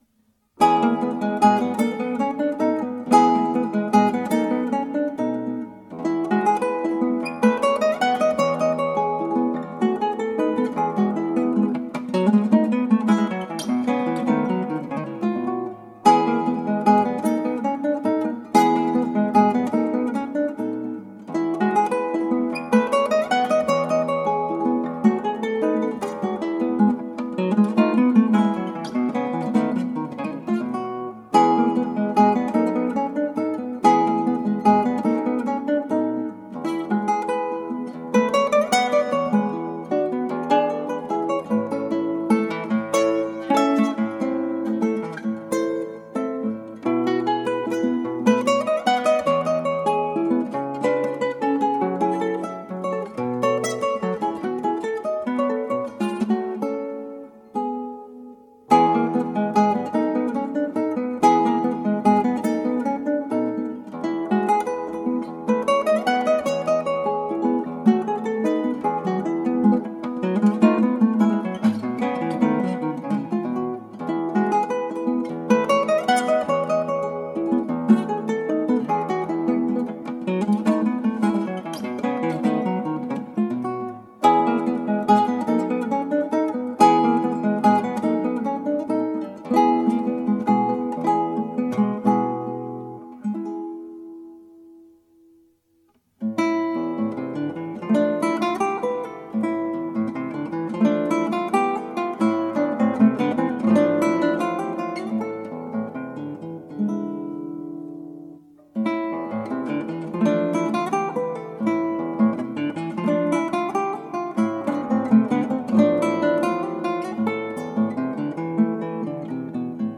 クラシックギター WBC優勝 - 「キューバ」
ギターの自演をストリーミングで提供